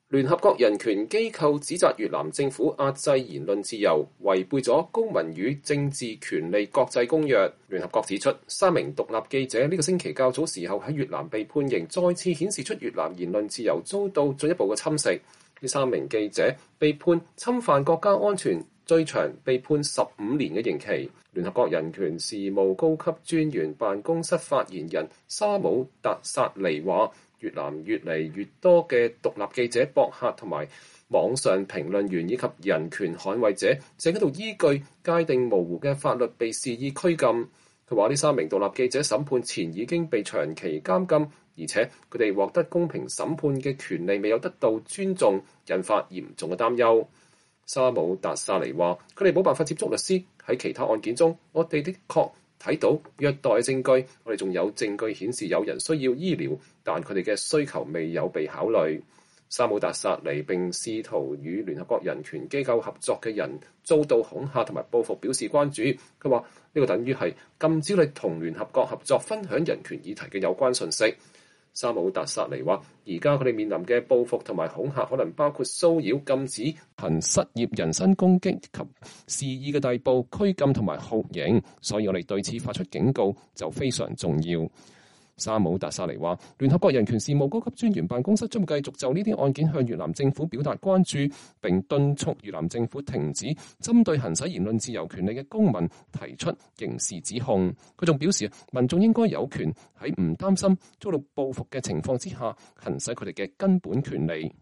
日内瓦 —